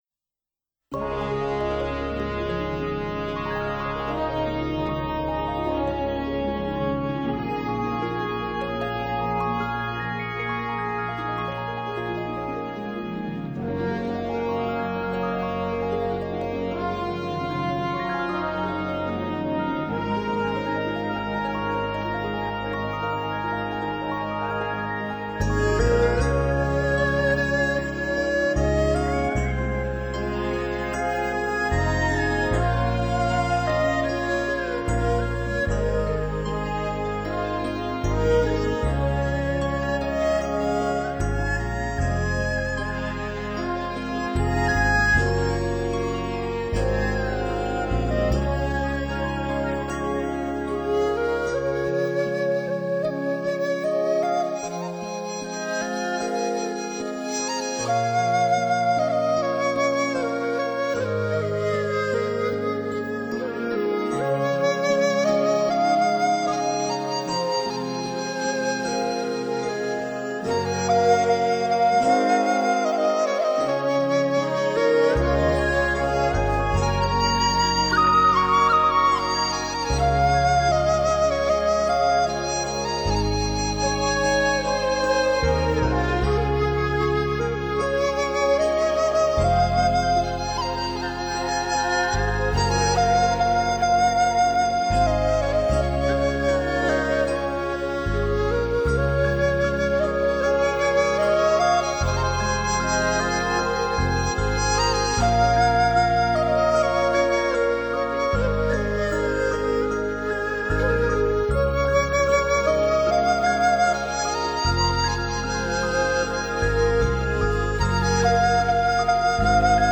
宗教音乐
音乐版